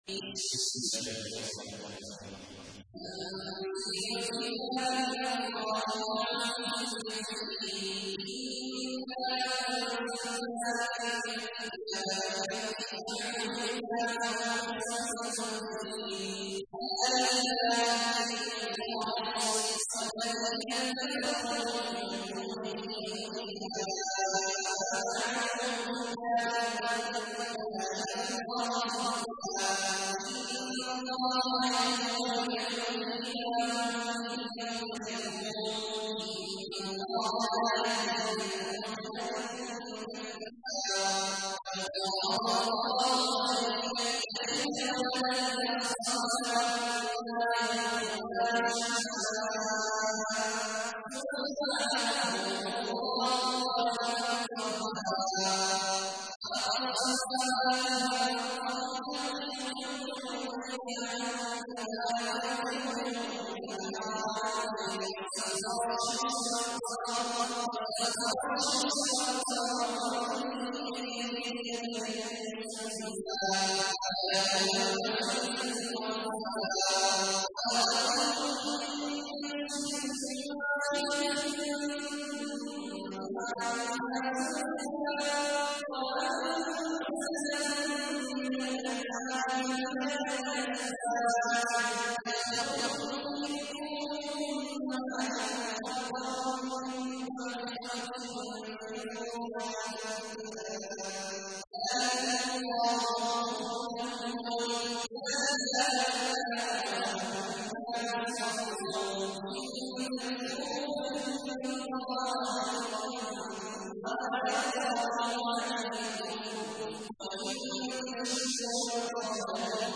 تحميل : 39. سورة الزمر / القارئ عبد الله عواد الجهني / القرآن الكريم / موقع يا حسين